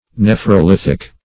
Search Result for " nephrolithic" : The Collaborative International Dictionary of English v.0.48: nephrolithic \neph`ro*lith"ic\, a. [Gr. nefro`s a kidney + -lith + ic.]
nephrolithic.mp3